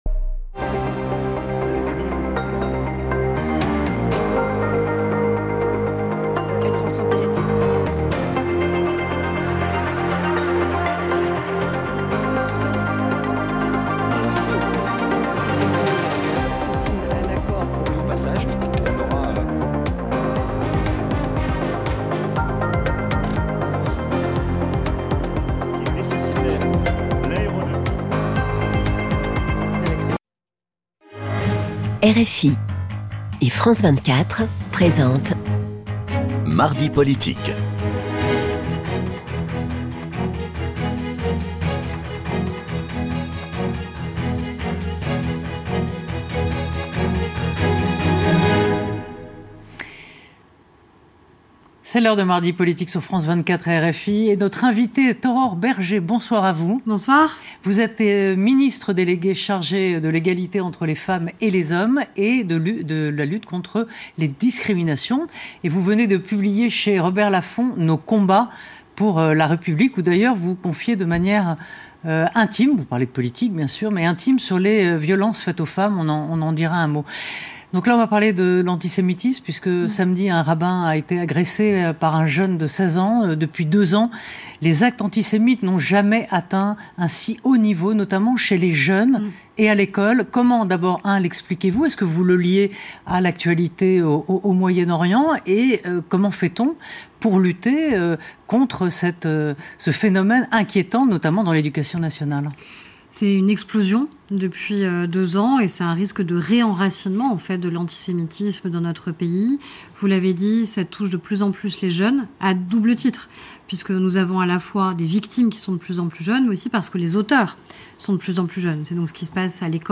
Aurore Bergé, ministre déléguée chargée de l’Égalité entre les femmes et les hommeset de la Lutte contre les discriminations est l'invitée de Mardi politique.
Aurore Bergé est interviewée par :